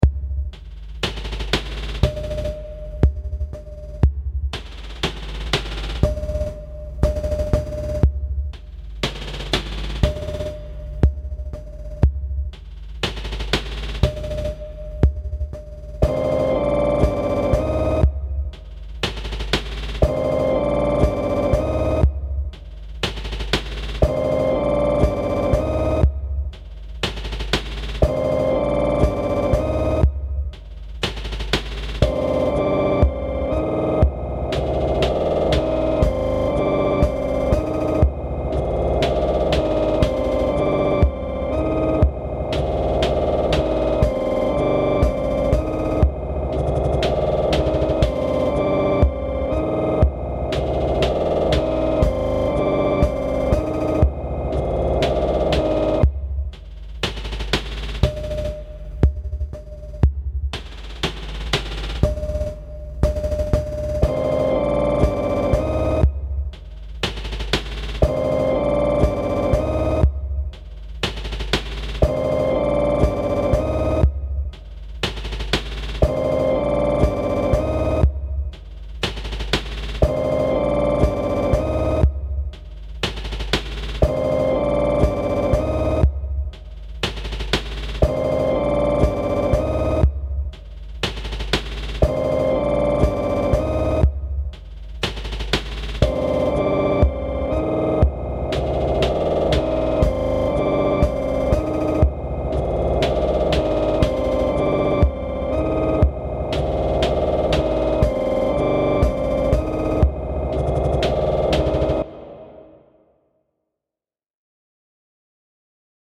Electrónica oscura